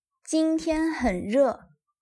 Jīntiān hěn rè
ジンティェン ヘン ルァ